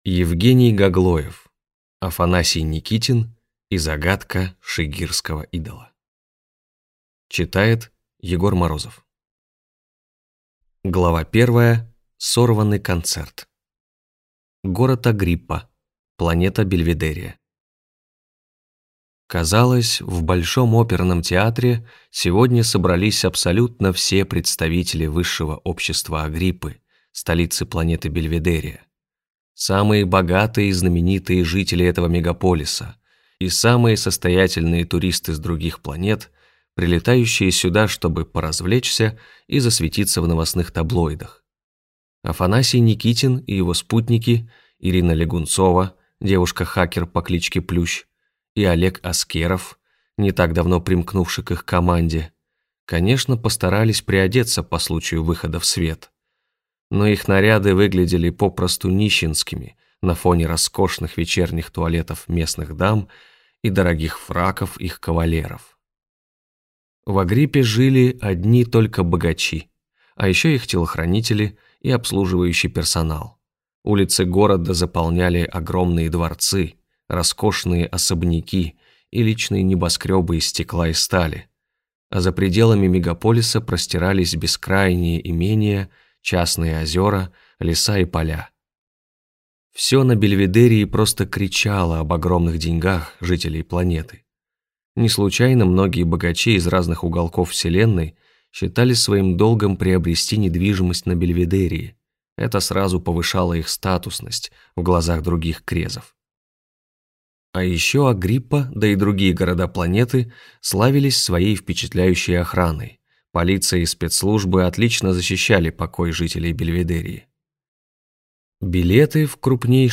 Аудиокнига Афанасий Никитин и загадка Шигирского идола | Библиотека аудиокниг